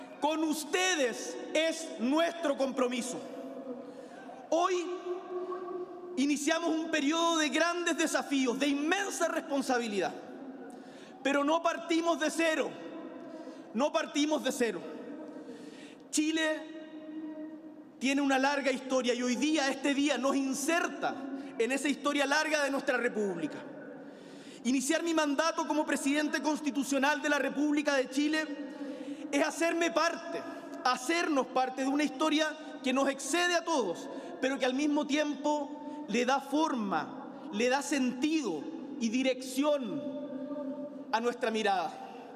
Esa noche, desde uno de los balcones de La Moneda, el mandatario daba inicio a un período “de grandes desafíos y de inmensa responsabilidad”